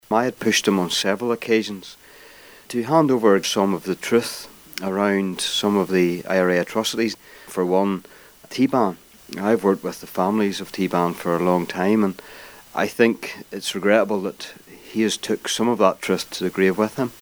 The North Antrim Assemblyman was speaking following the death of the former Mid-Ulster MLA and MP.